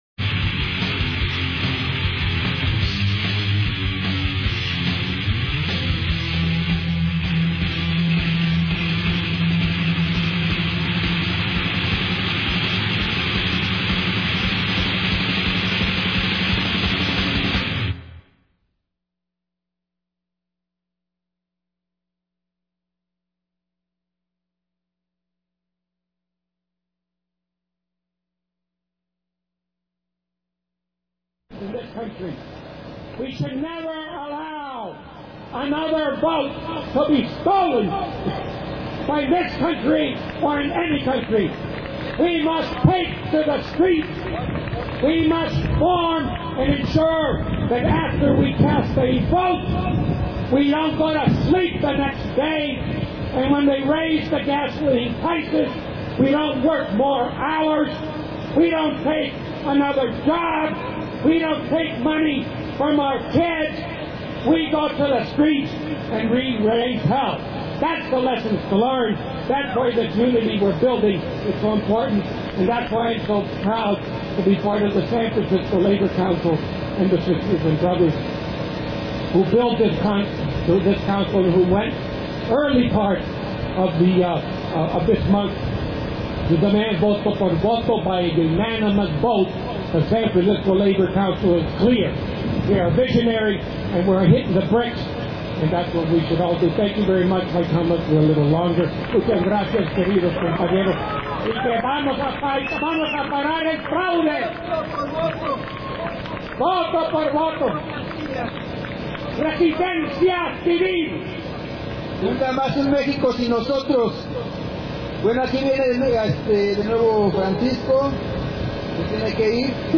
Julio 30 2006, manifestacion contra el fraude electoral y por la democracia en Mexico.